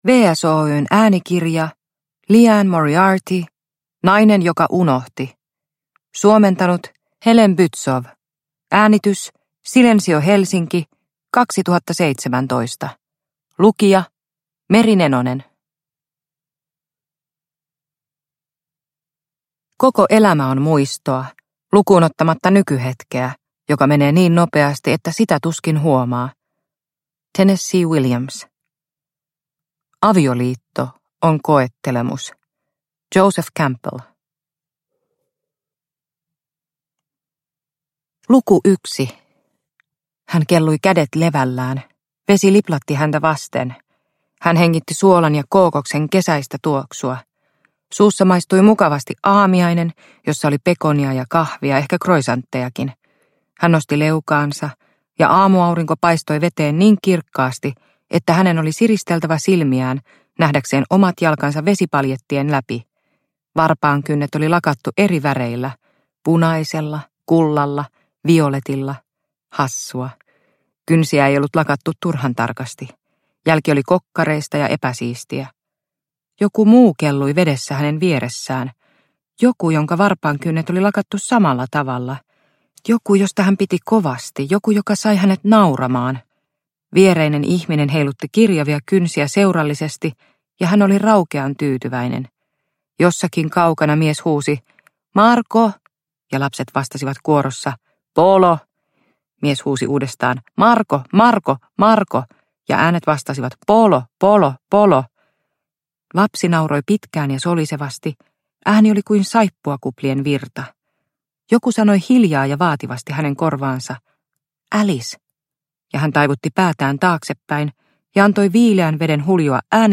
Nainen joka unohti – Ljudbok – Laddas ner